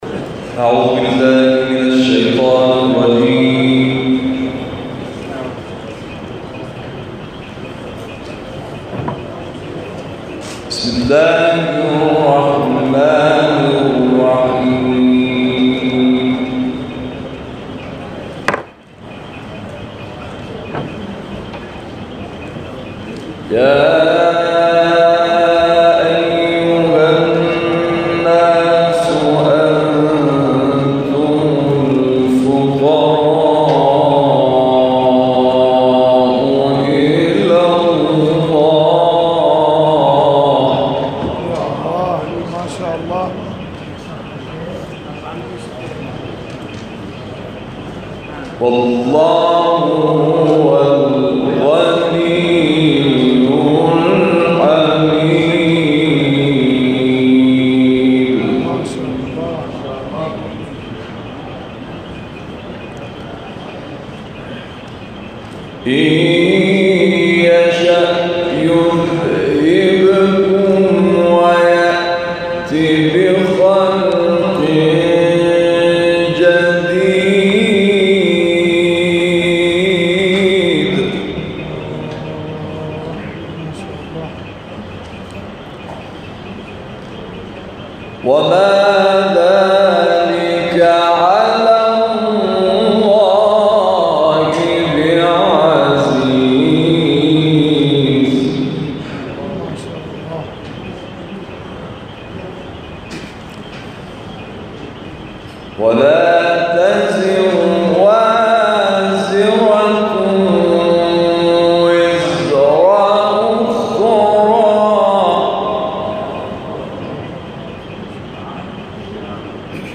تلاوت در نجف اشرف از آیه 15 الی 24 سوره فاطر و سوره قدر ارائه می‌شود.